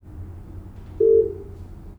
Binaural recordings of 400 Hz tones with 50 Hz sinusoidal amplitude modulations (0-100% depth) played from a loudspeaker in my dining room.
Binaural recordings for 0° azimuth, cropped 1 second before and after the recorded tone’s onset.